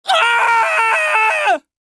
crying-men-sound